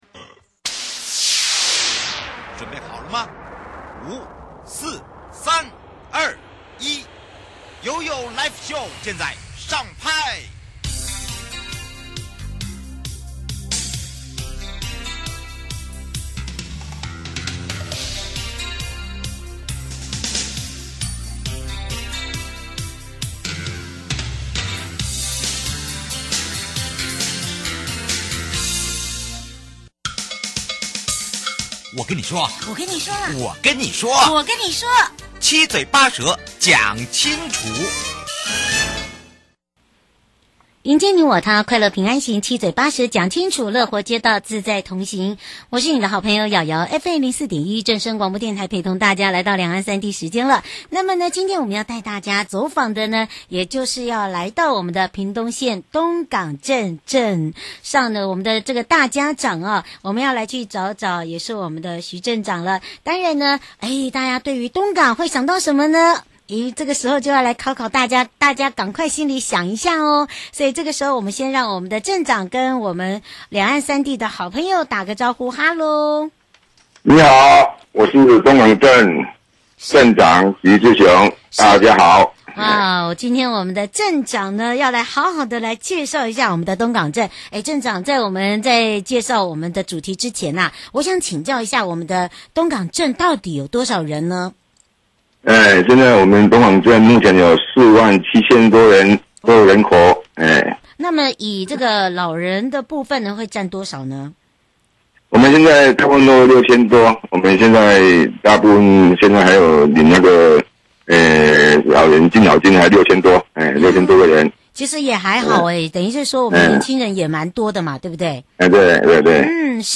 受訪者： 營建你我他-快樂平安行-七嘴八舌講清楚- 中央前瞻提升道路品質計畫對屏東縣東港鎮有何助益?民眾對計畫反應如何?有什麼政策來推動通行無礙人行環境?(上集) 節目內容： 屏東縣東港鎮公所徐志雄鎮長